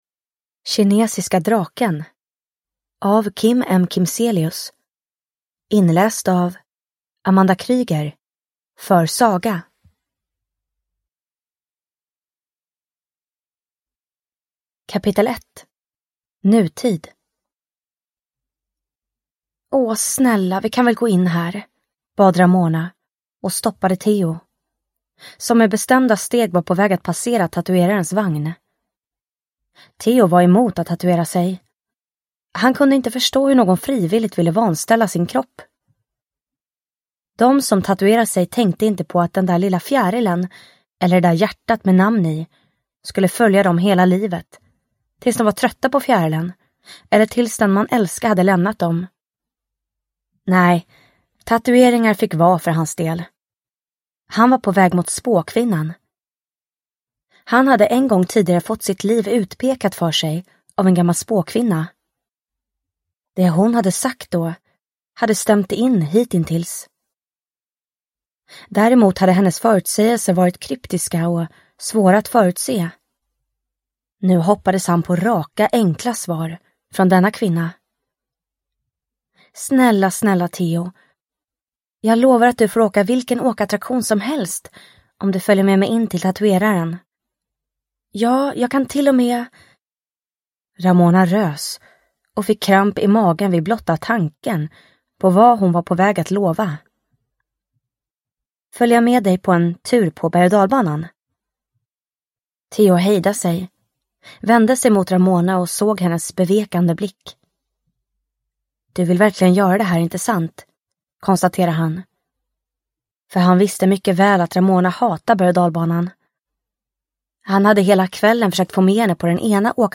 Kinesiska draken / Ljudbok